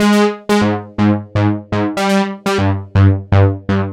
Funk House_122_Ab.wav